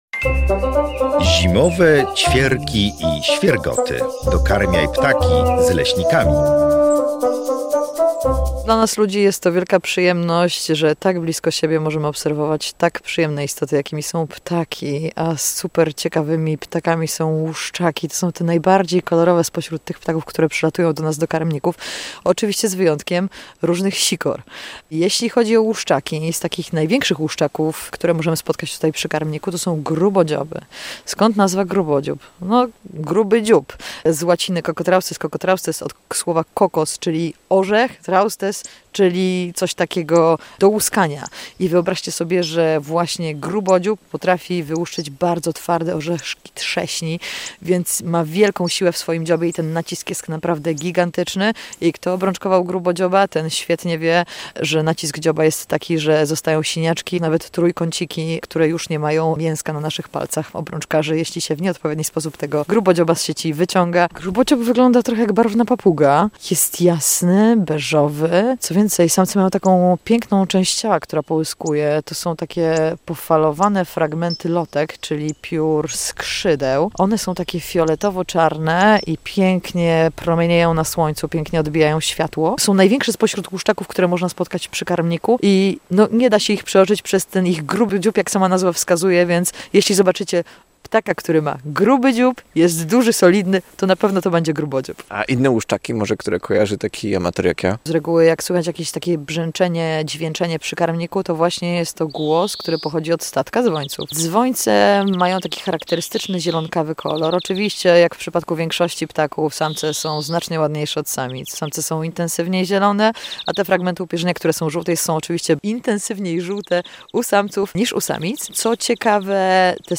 Tym razem wybraliśmy się na spacer do Białowieskiego Parku Narodowego.